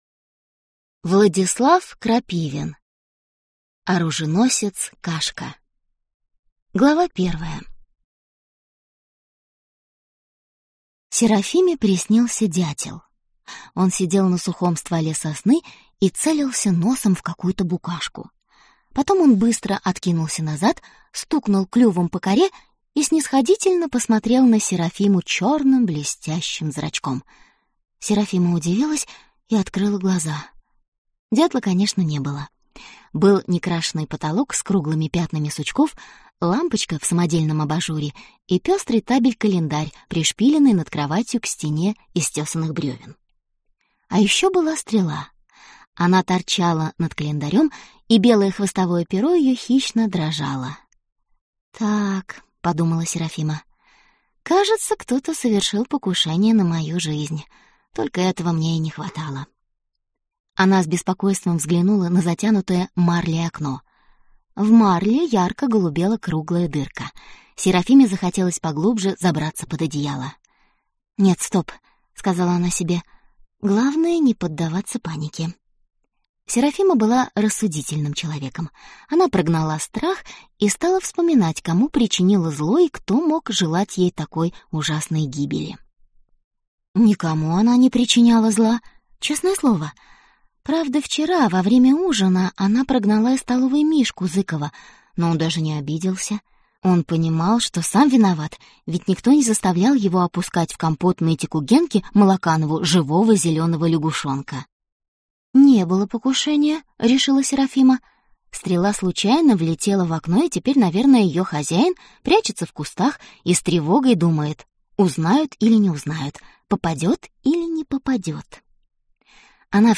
Аудиокнига Оруженосец Кашка | Библиотека аудиокниг